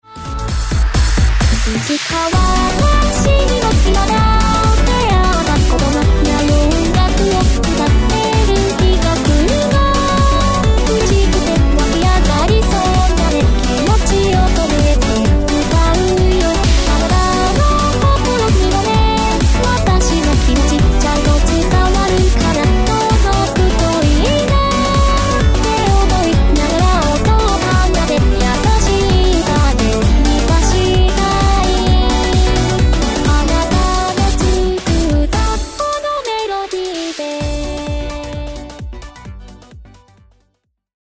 ロック・ポップス・トランス・ヒーリング等の様々な音楽に載せて一足先に